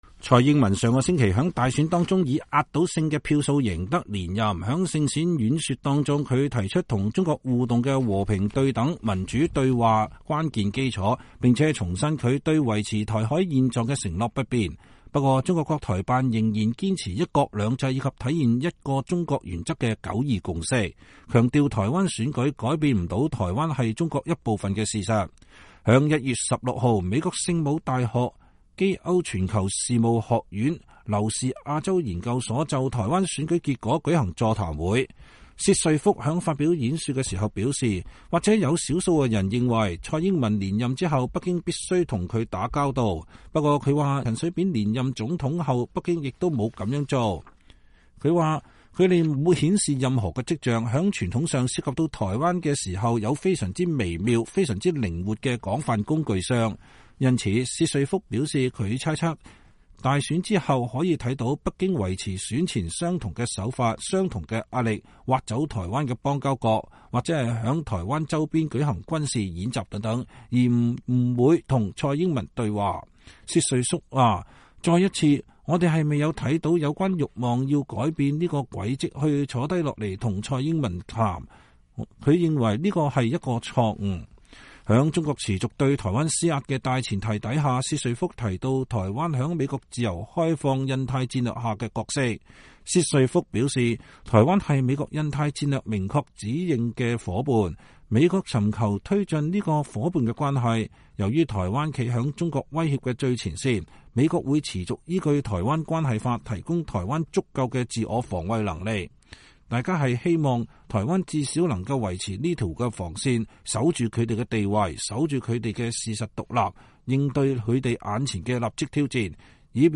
星期四(1月16日)，美國聖母大學(University of Notre Dame)基歐全球事務學院(Keough School of Global Affairs)劉氏亞洲研究所就台灣選舉結果舉行座談會，薛瑞福(Randall Schriver)在發表演說時表示，或許有少數人認為，蔡英文連任後北京必須與她打交道，不過他說，2004、2005年陳水扁連任總統後北京也沒有這麼做。